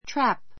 trap A2 trǽp ト ラ プ